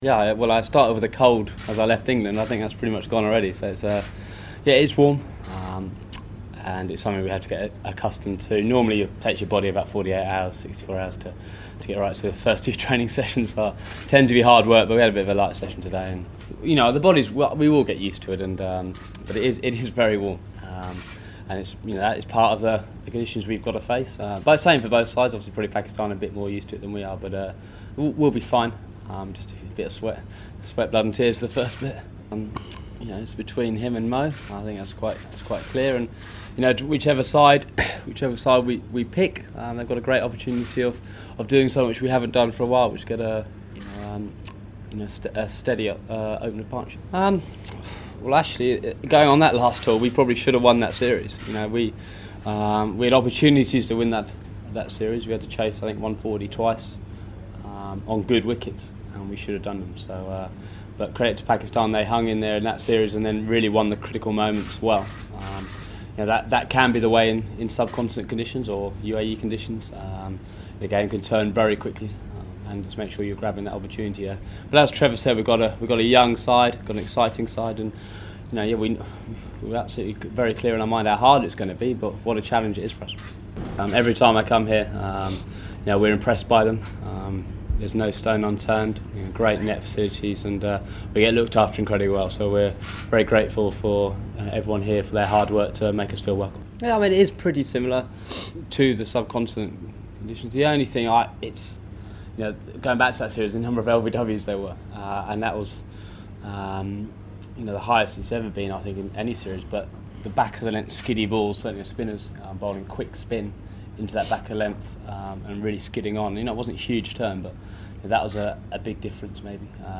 Alastair Cook, the England captain, media conference, 2 October